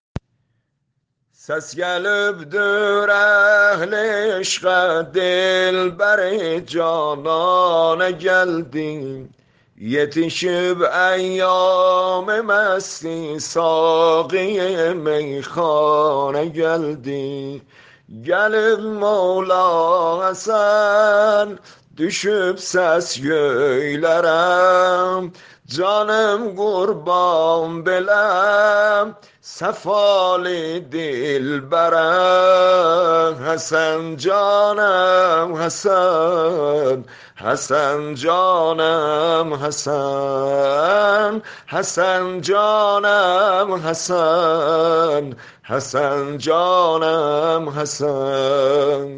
با صدای استاد بزرگوارم